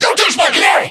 mech_mike_hurt_vo_02.ogg